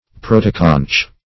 Search Result for " protoconch" : The Collaborative International Dictionary of English v.0.48: Protoconch \Pro"to*conch\, n. [Proto- + conch.]